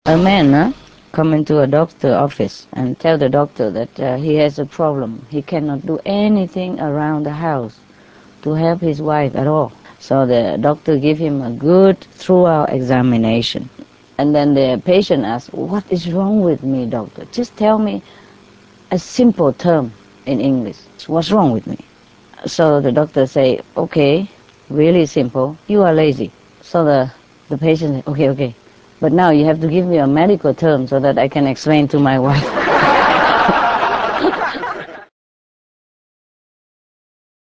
Master Tells Jokes
Spoken by Supreme Master Ching Hai, August 23, 2007,
Paris 3-Day Retreat, France (Originally in English)